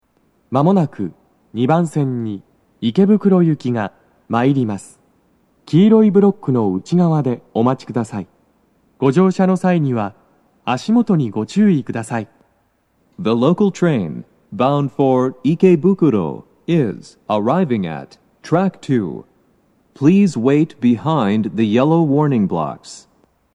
スピーカー種類 BOSE天井型
鳴動は、やや遅めです。
2番線 池袋方面 接近放送 【男声